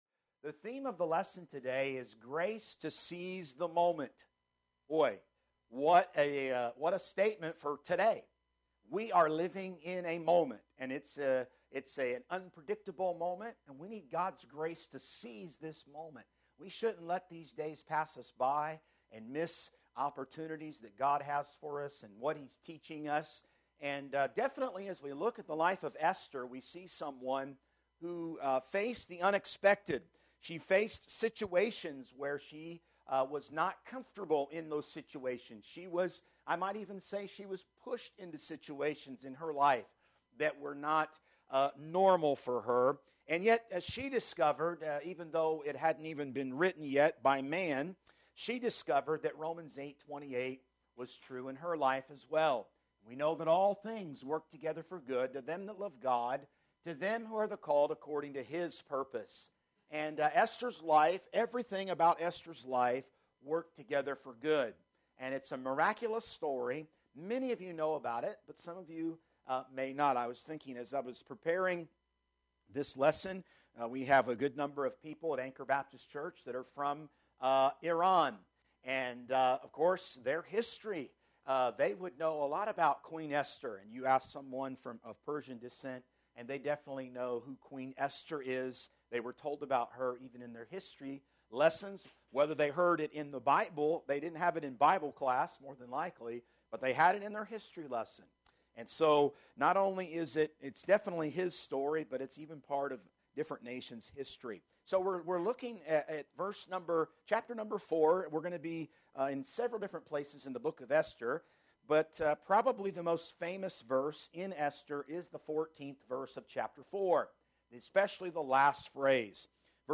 Sermons | Anchor Baptist Church